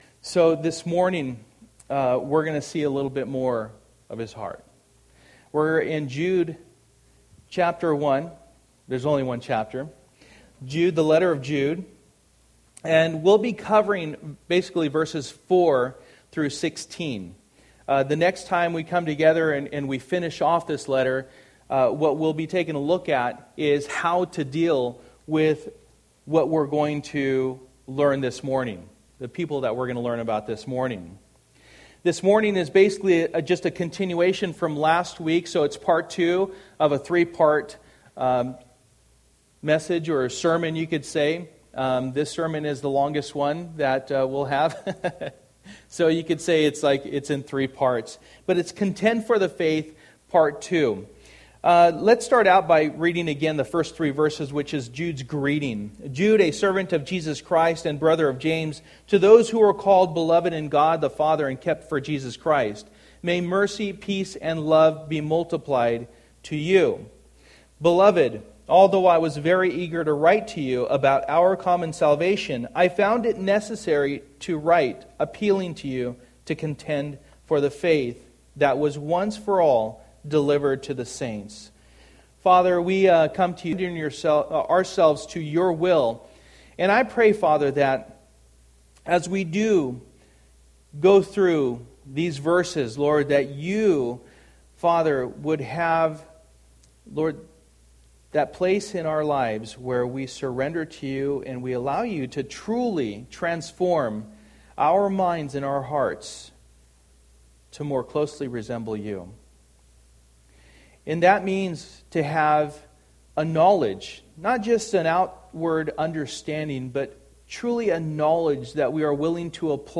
Jude 1:4-16 Service: Sunday Morning %todo_render% « Time to Man Up Joseph